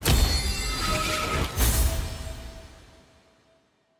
sfx-honor-votingceremony-castvote.ogg